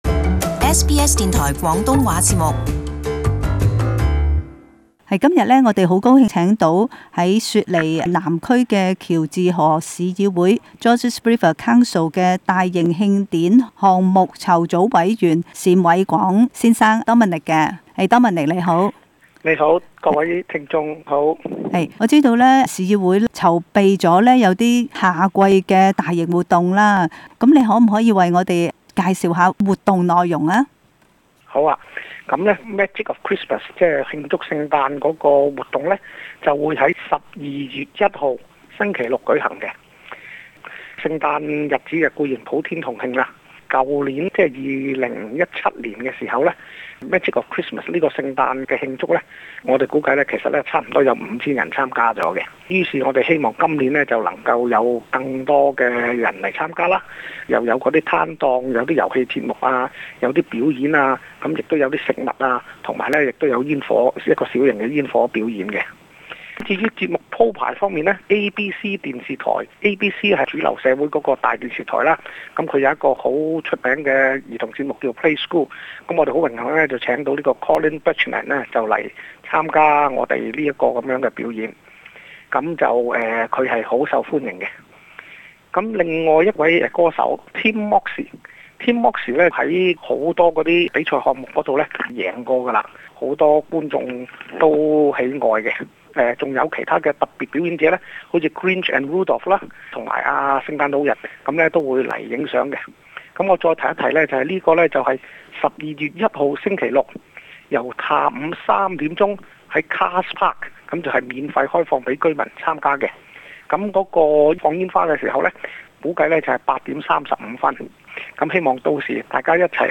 【雪梨社區專訪】喬治河區夏季大型活動